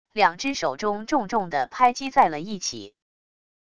两只手中重重的拍击在了一起wav音频